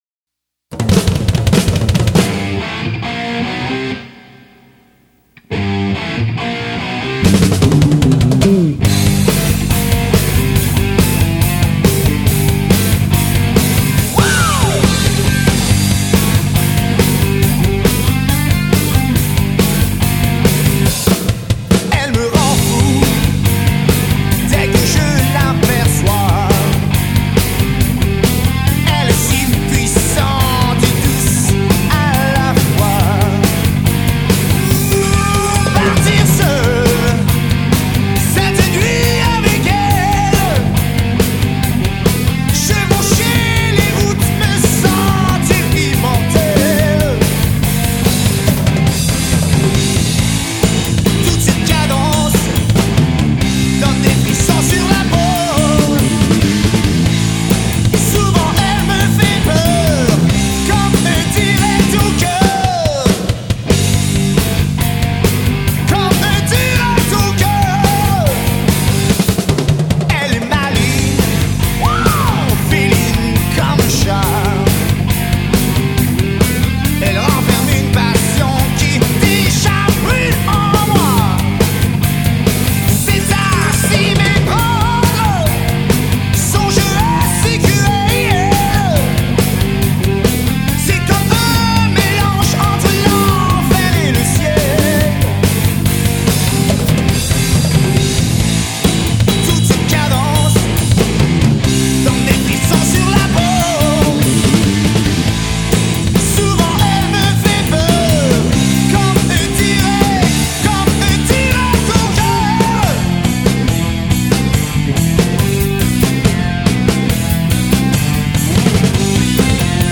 formation Rock Francophone constituée de quatre membres